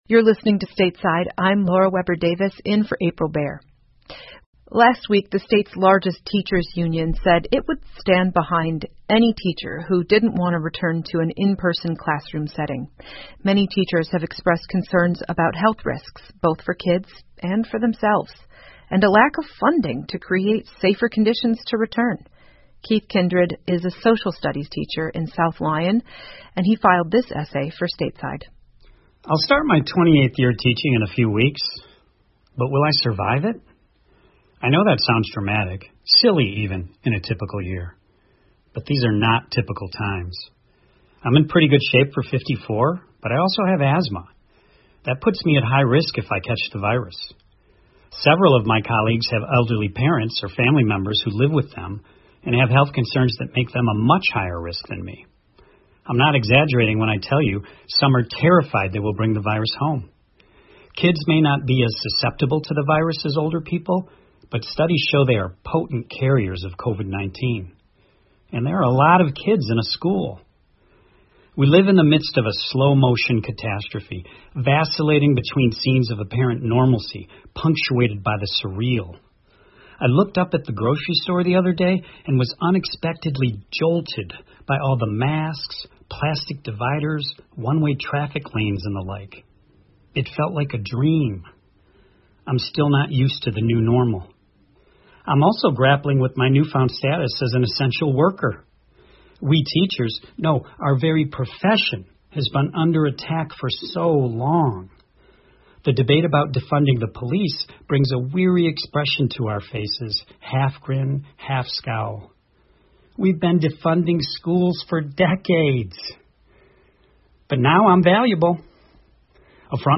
密歇根新闻广播 教师们的担忧 听力文件下载—在线英语听力室